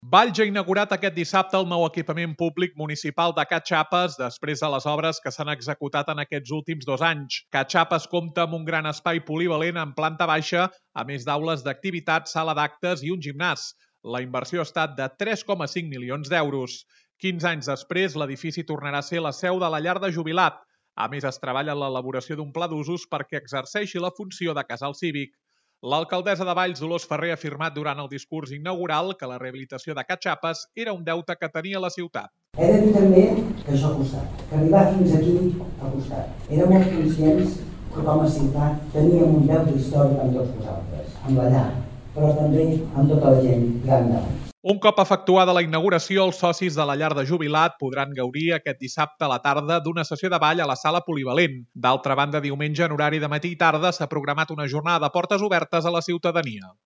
L’alcaldessa de Valls, Dolors Farré, ha afirmat durant el discurs inaugural que la rehabilitació de Ca Xapes era un deute que tenia la ciutat.